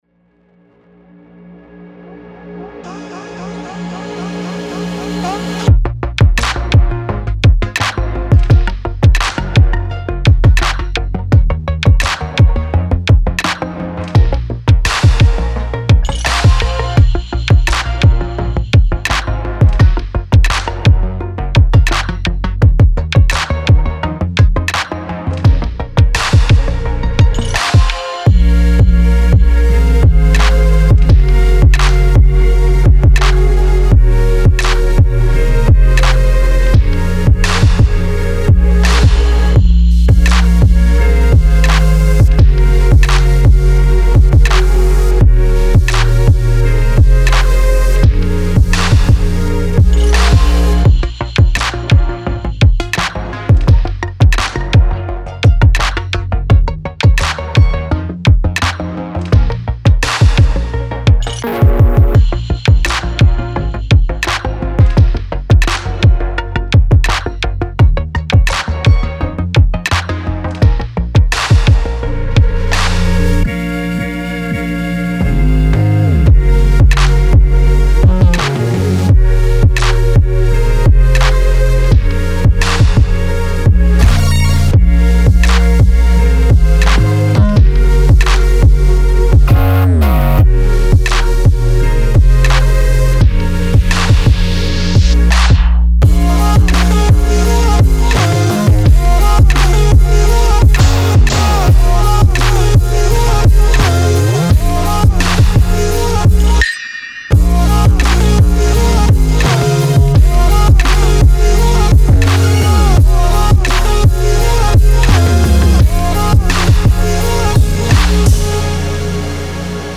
FUTURISTIC SYNTH-POP
Dark / Energetic / Inspiring / Gritty